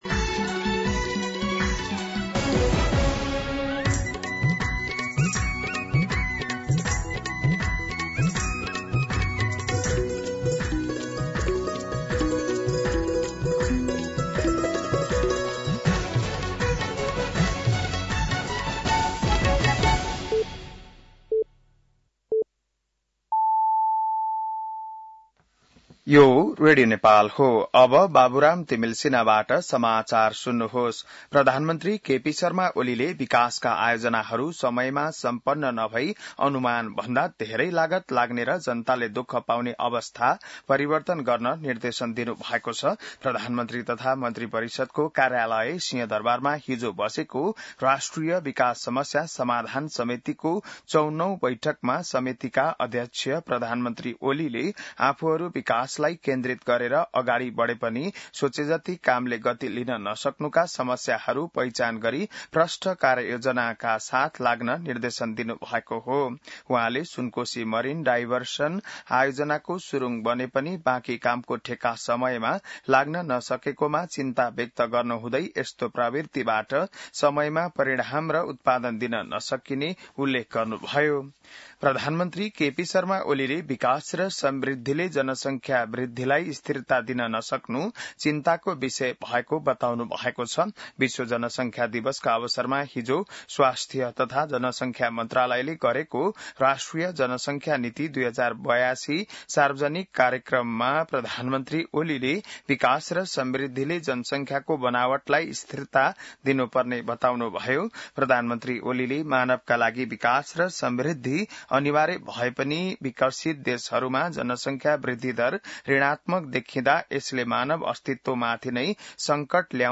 बिहान ११ बजेको नेपाली समाचार : २८ असार , २०८२